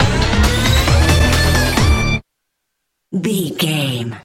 Aeolian/Minor
hard rock
metal
lead guitar
bass
drums
aggressive
energetic
intense
nu metal
alternative metal